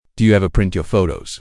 Part 1 (Introduction & Interview)